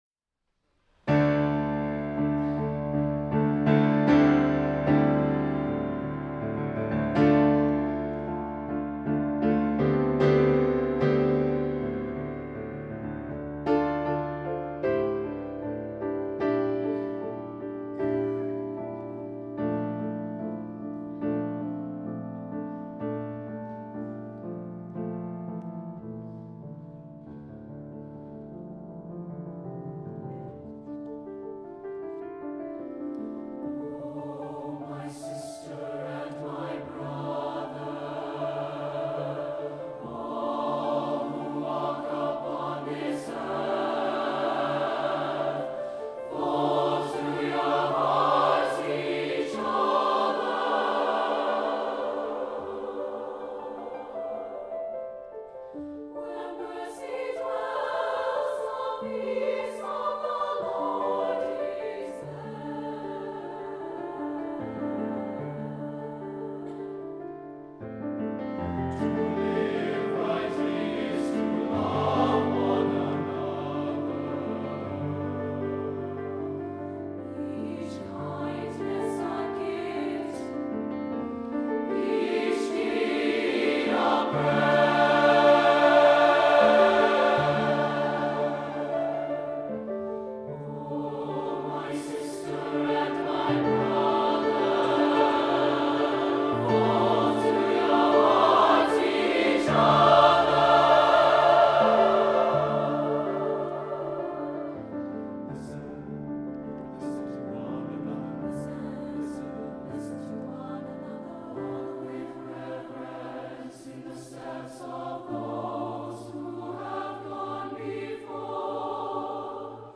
SATB version